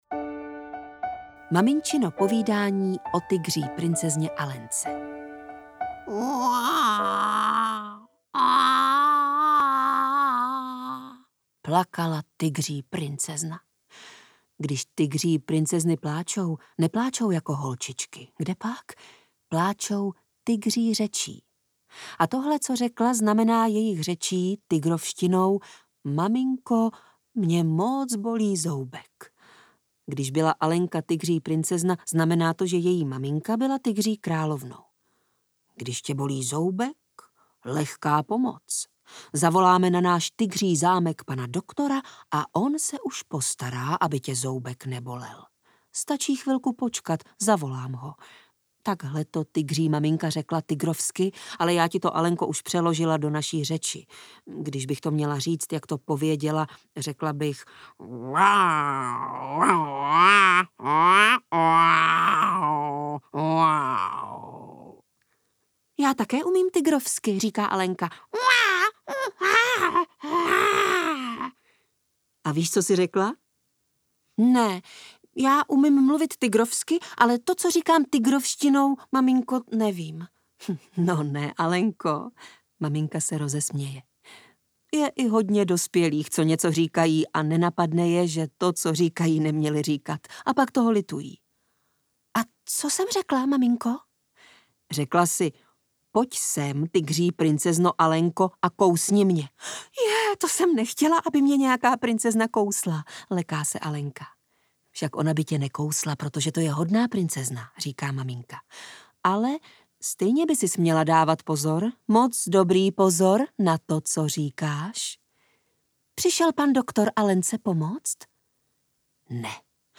Pohádková maminka a krtek audiokniha
Ukázka z knihy